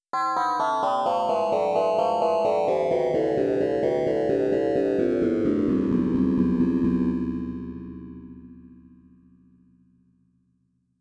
Music from Brownian motion
The following Python program demonstrates how we can we harness randomness to generate music that is more correlated, “natural” sounding.